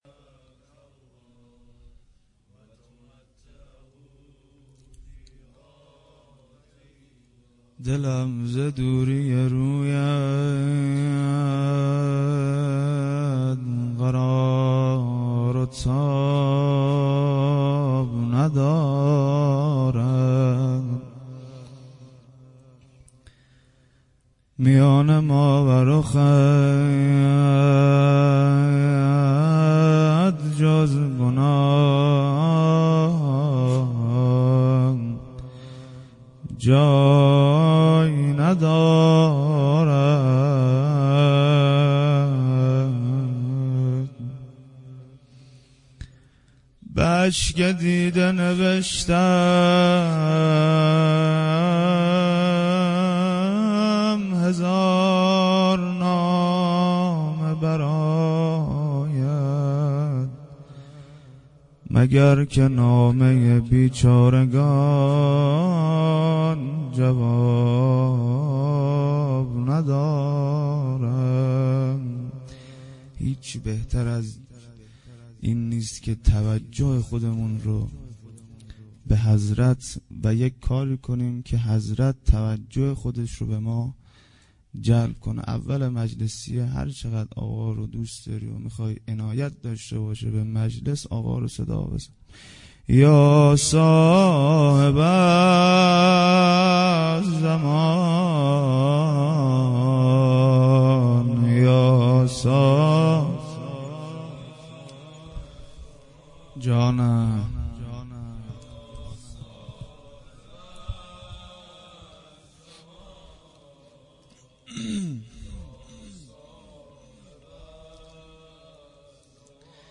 هیئت زواراباالمهدی(ع) بابلسر
سخنرانی